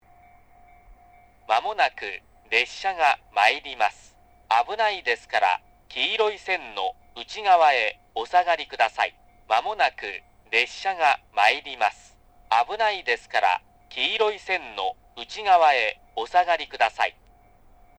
接近放送　男声 放送は九州カンノ型Cですが、旧放送同様音量が「超激小」なのは変わりありません。接近ベルが鳴らず、放送のみ2回鳴るという、カンノ型Cではここだけのパターンとなっています。
スピーカーはFPS平面波、JVCラインアレイと、TOA円型ワイドホーン、TOAラッパがありますが一部音が出ないものもあり、個人的には1番のりばは低い位置にあるFPS、2番のりばは鳥栖寄りのTOA円型が良いかと思います。